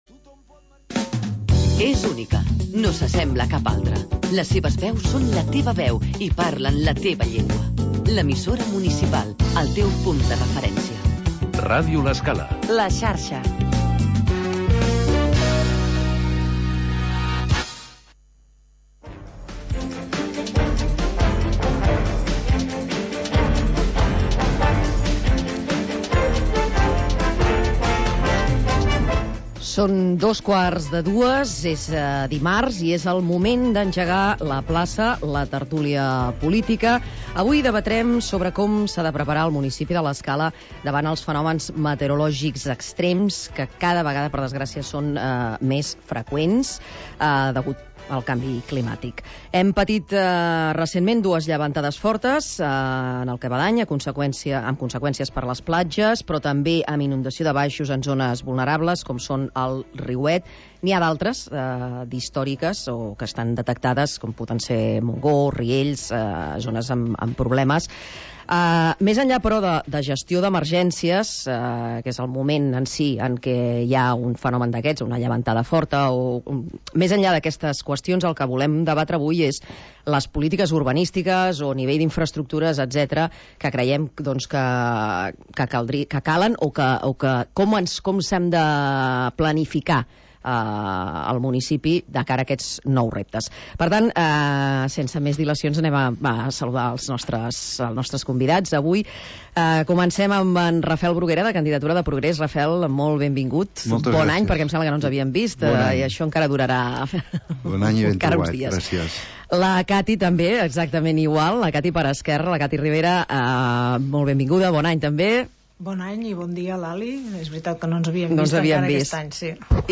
Tertúlia de caire polític